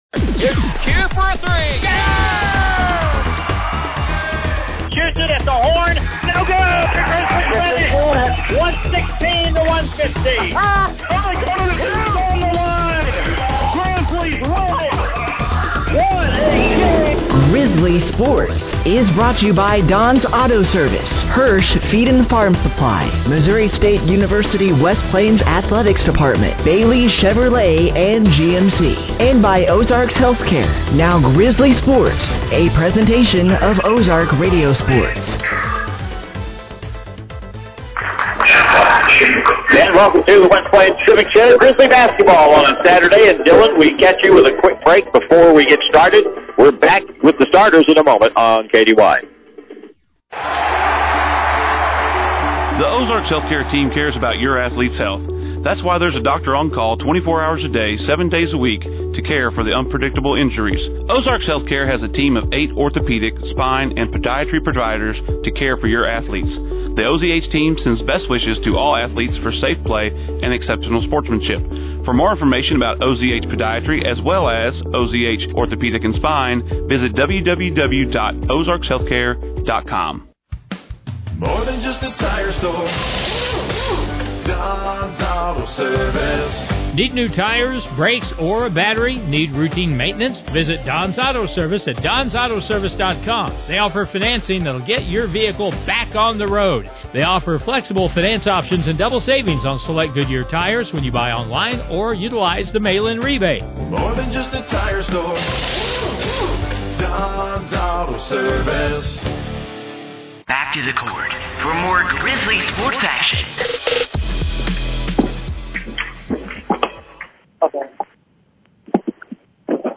Game Audio Below: The Missouri State West Plains Grizzly Basketball Team resumed play in The Grizzly Tournament for their second matchup in what would be their home tournament.